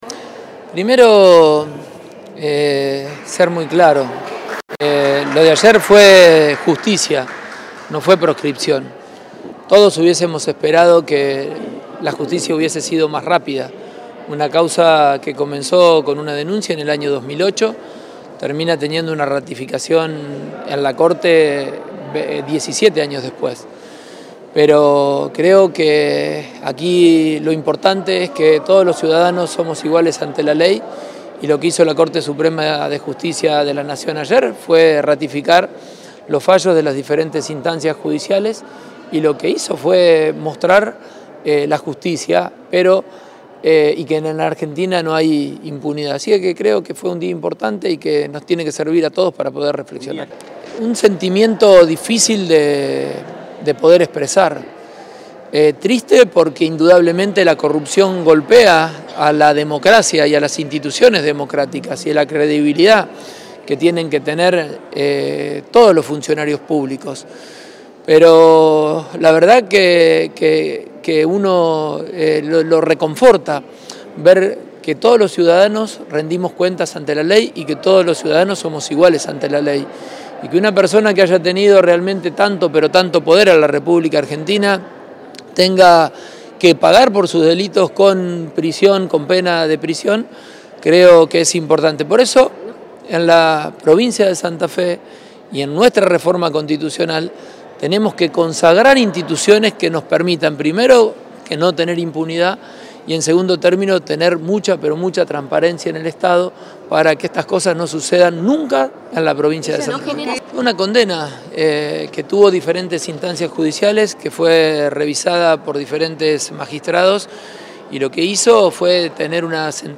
“Hay que ser muy claros, lo de ayer fue justicia, no proscripción”, apuntó el mandatario durante la conferencia de prensa en Casa de Gobierno.